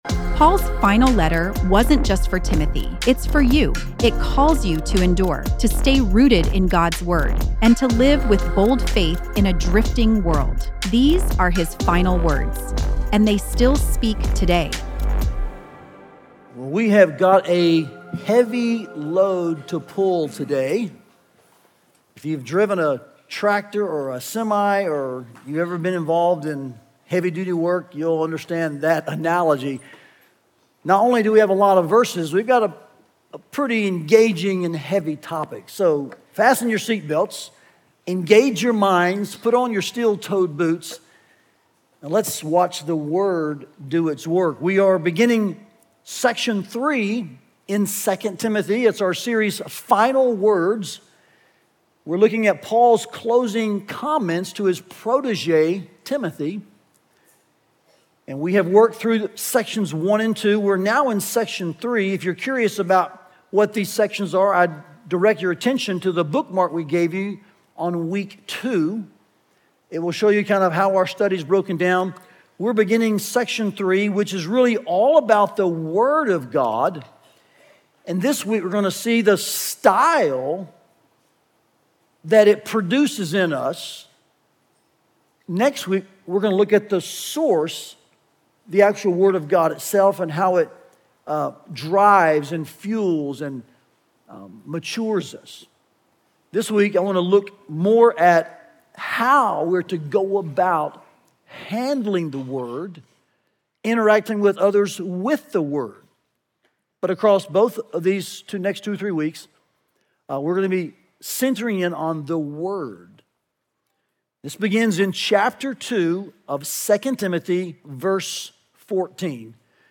Listen to the latest sermon from our 2 Timothy series, “Final Words”, and learn more about the series here.